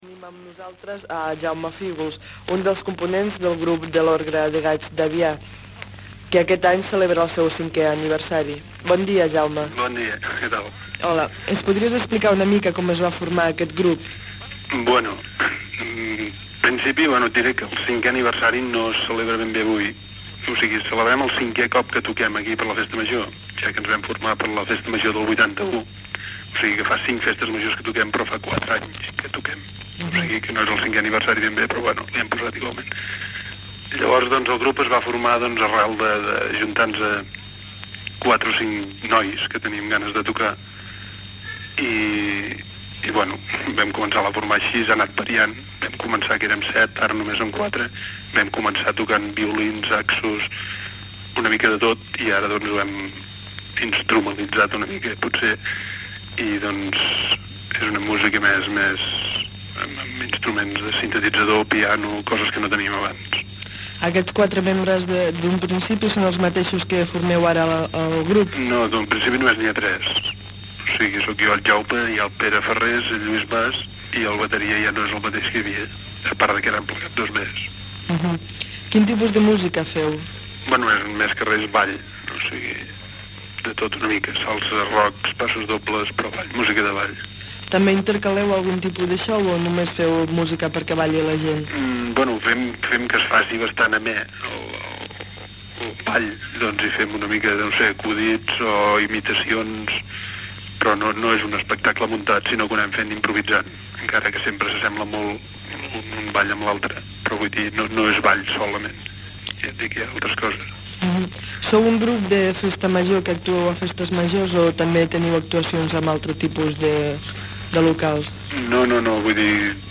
Entrevista
indicatiu estiuenc de la ràdio